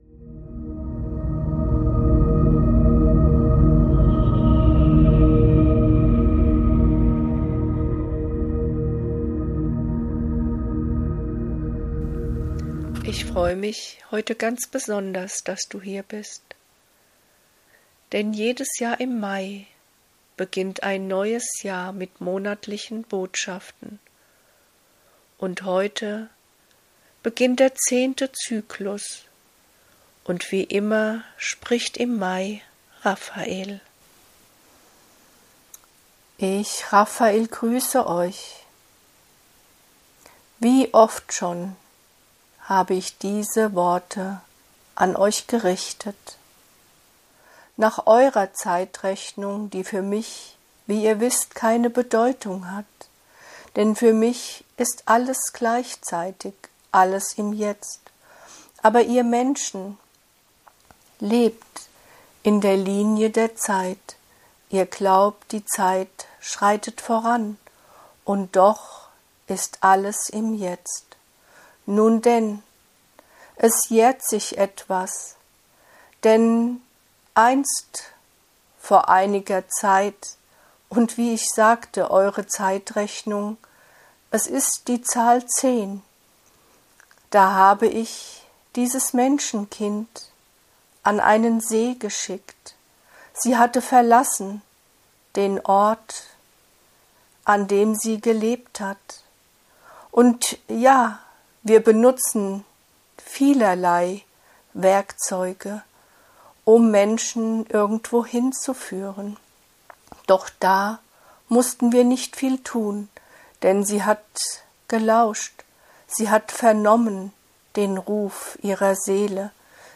In meinem Podcast findest du durch mich direkt gechannelte Lichtbotschaften. Wundervoll geeignet zum meditieren, vom Alltag abschalten und abtauchen in eine andere Ebene des Seins.